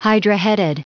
Prononciation du mot hydra-headed en anglais (fichier audio)
Prononciation du mot : hydra-headed